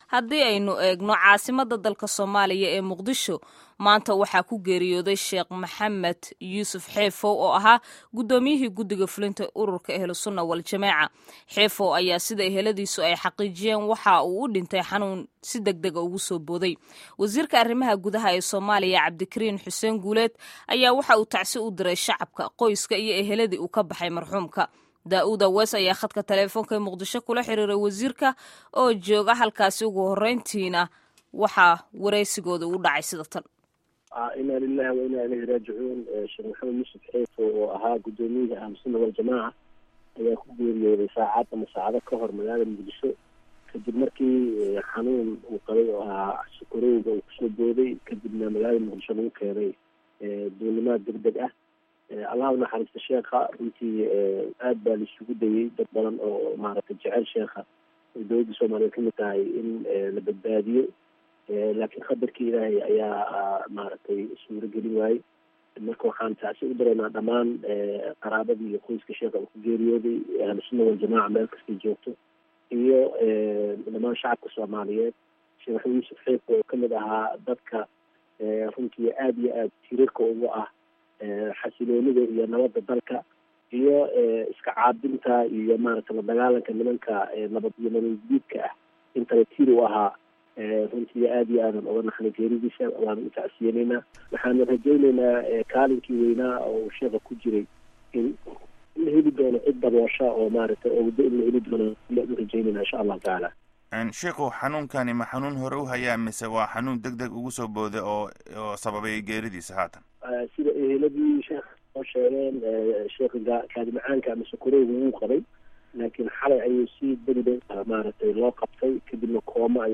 Wareysiga Wasiirka Arrimaha Gudaha ee Soomaaliya